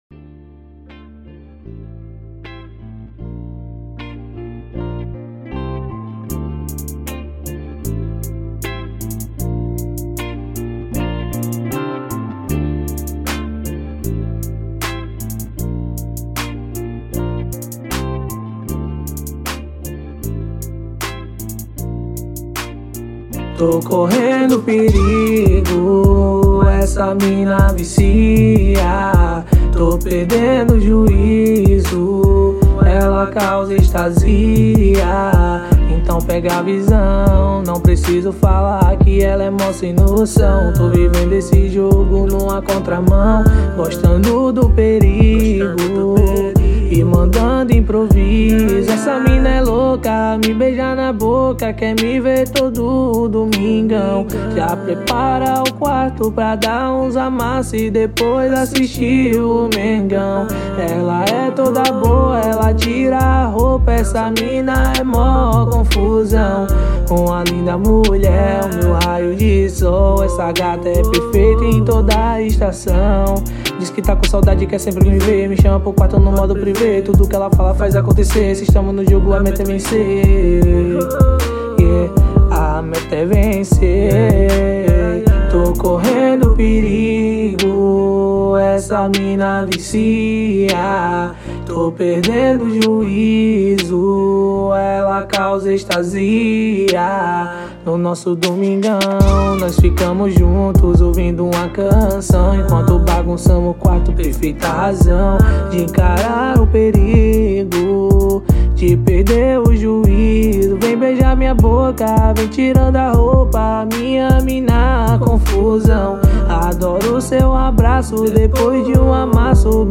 EstiloR&B